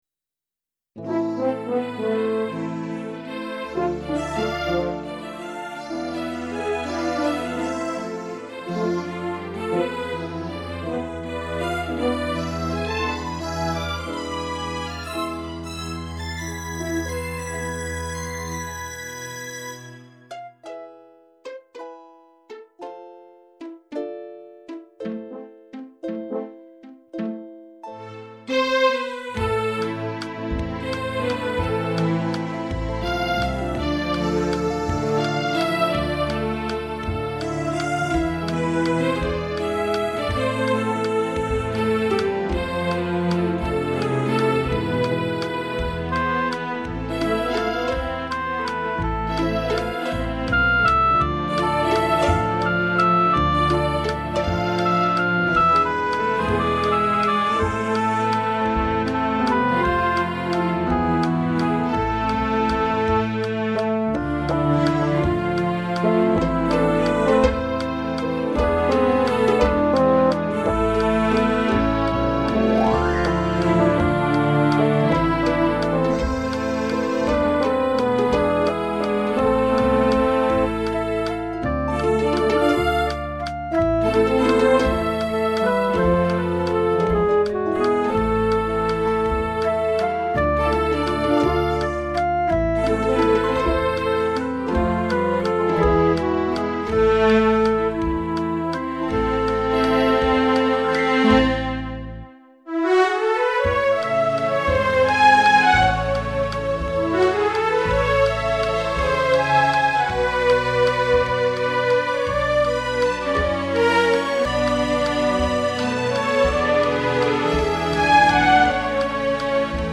Tío Ernesto. Este vals que cuenta de tres movimientos es una obra conjunta de los hermanos Ramos